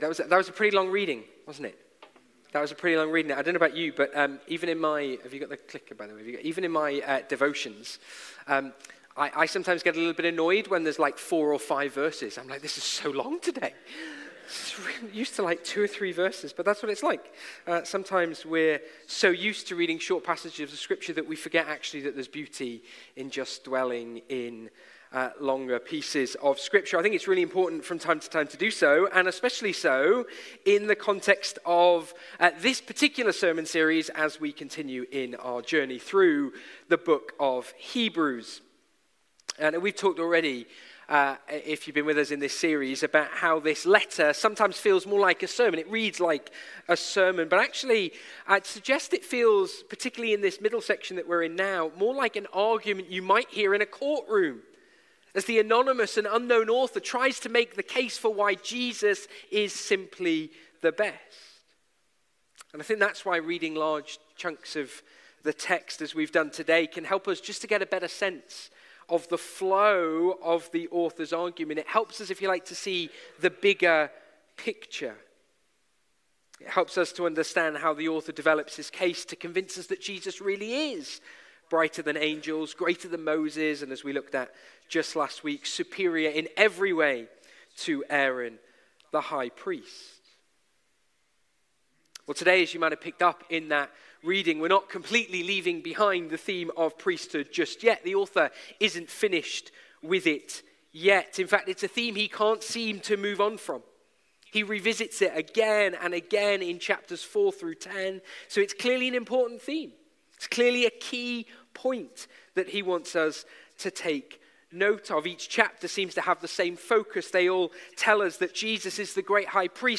Passage: Hebrews 7:11–8:13 Service Type: Sunday Morning